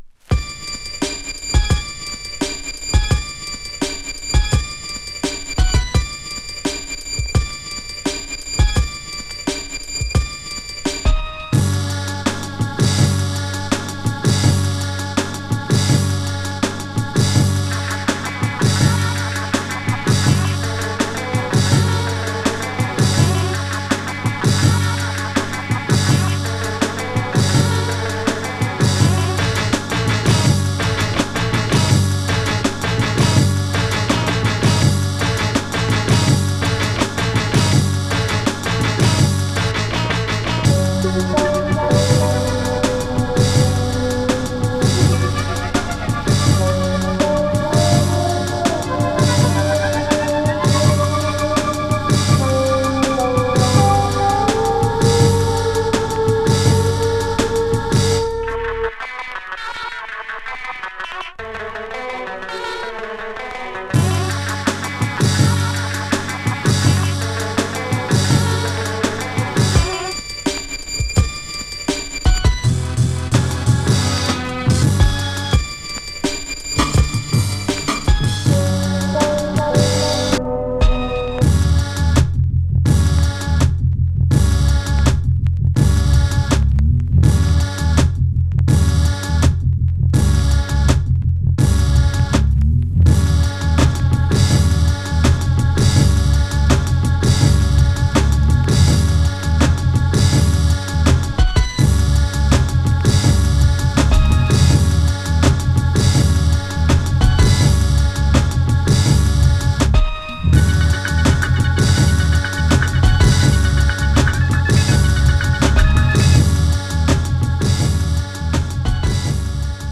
> JAZZY BREAK/ELECTRONICA/ABSTRACT